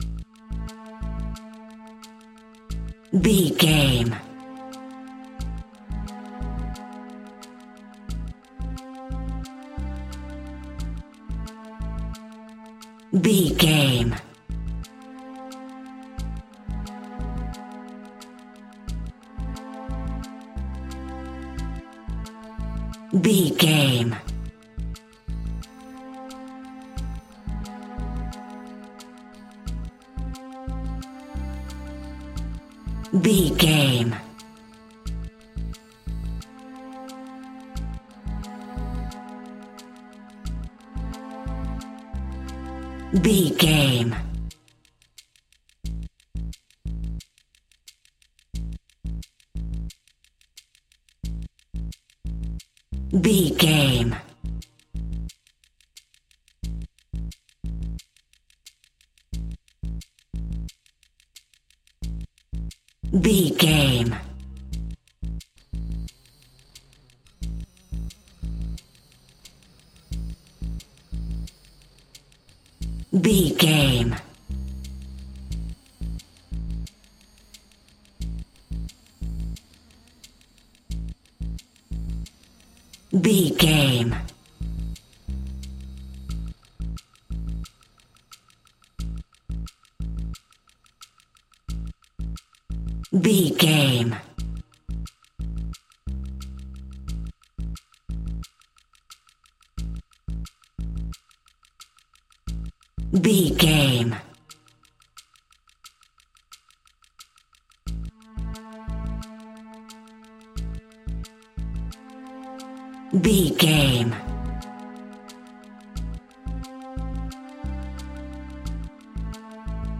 Building up the Tension.
In-crescendo
Thriller
Aeolian/Minor
scary
ominous
dark
suspense
haunting
eerie
bass guitar
strings
drum machine
instrumentals